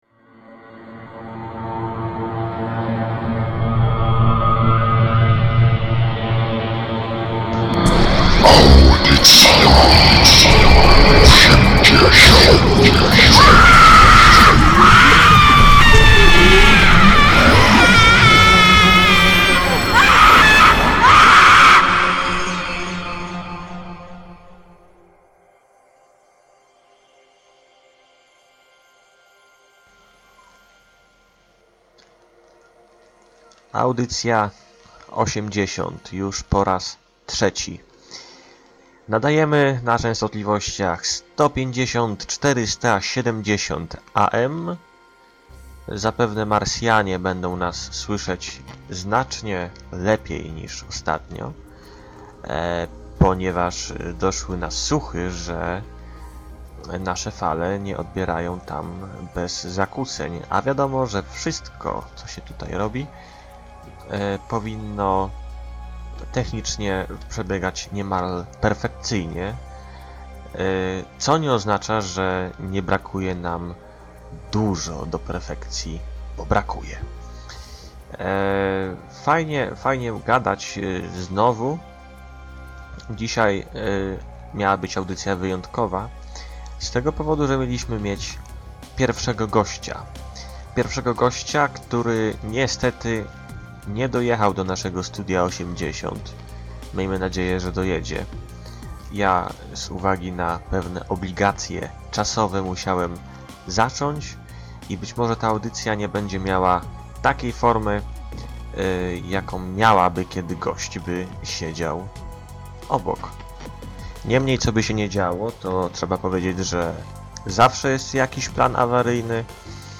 Każda audycja obfituje w masę niekonwencjonalnej muzyki, granej przez wyjątkowych, aczkolwiek bardzo często niszowych artystów.